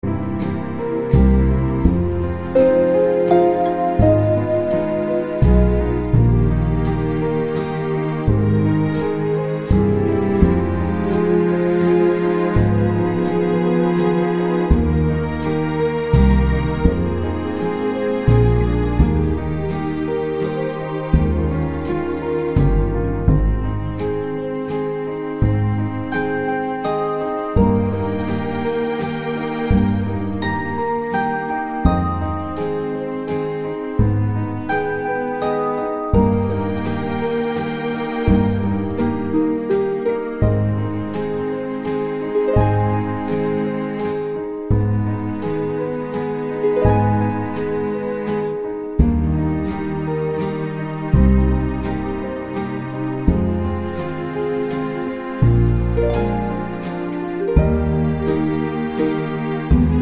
no Backing Vocals Crooners 2:51 Buy £1.50